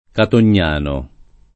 Catognano [ katon’n’ # no ] → Cattognano